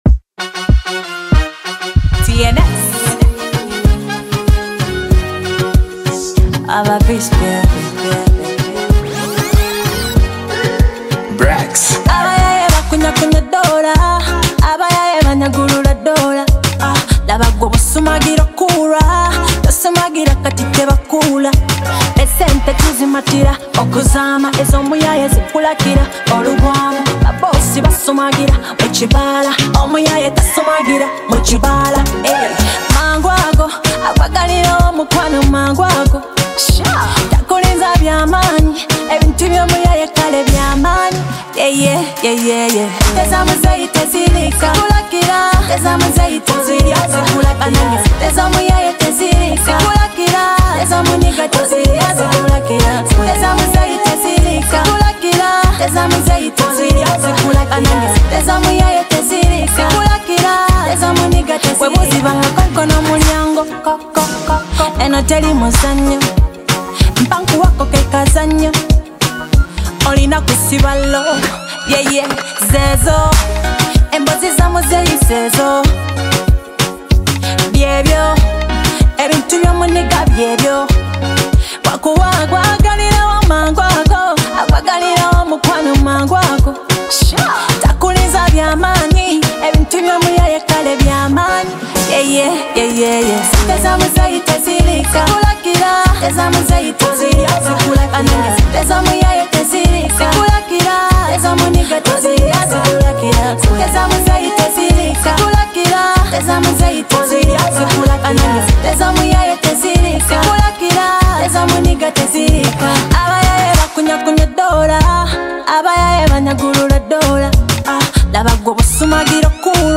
soulful vocals
contemporary rhythms